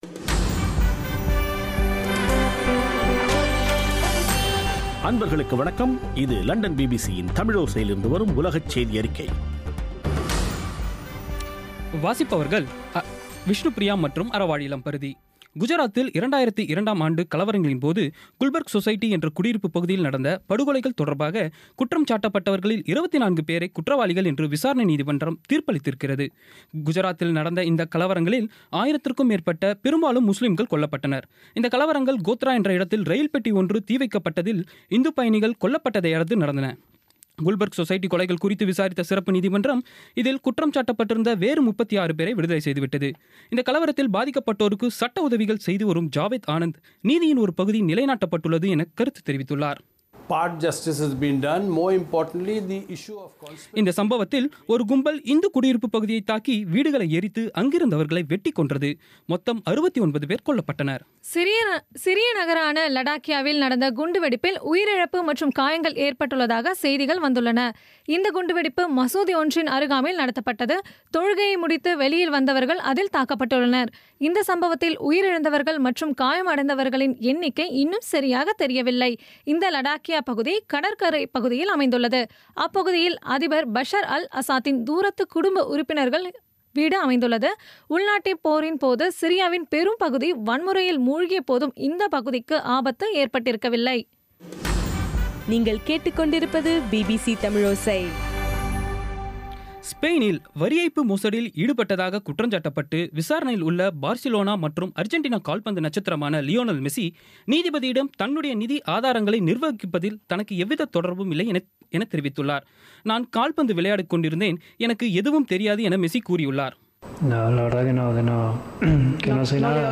இன்றைய பிபிசி(2/6/16) தமிழோசையின் செய்தியறிக்கை